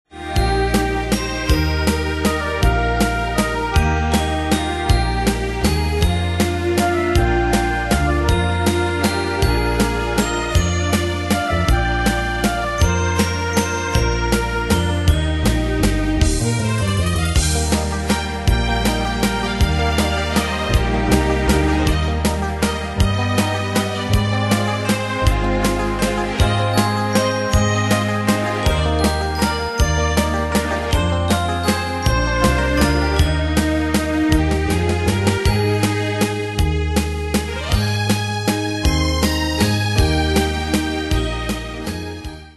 Style: PopAnglo Ane/Year: 1978 Tempo: 159 Durée/Time: 3.14
Danse/Dance: Waltz/Valse Cat Id.
Pro Backing Tracks